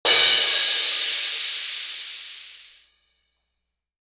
Crashes & Cymbals
Sizzle Out.wav